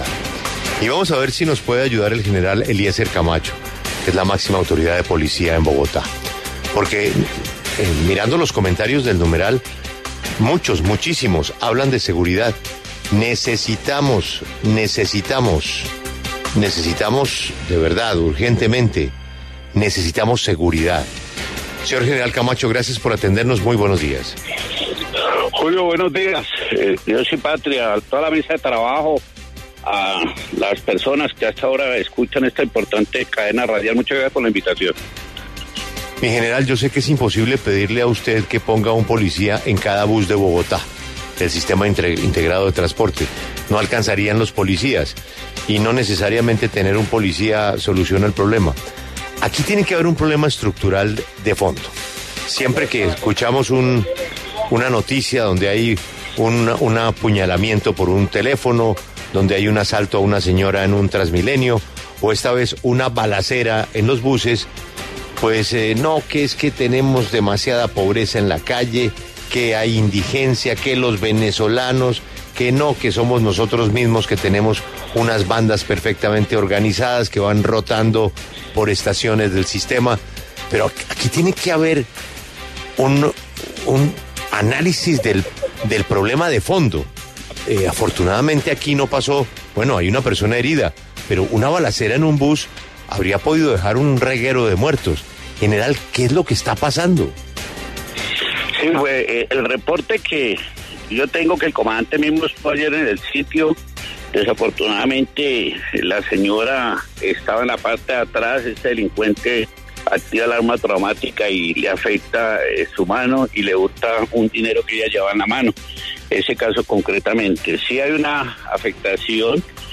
En diálogo con W Radio, el general Eliécer Camacho, comandante de la Policía Metropolitana de Bogotá, se refirió a las acciones que han adoptado las autoridades para contener los hechos delincuenciales que siguen ocurriendo en la ciudad.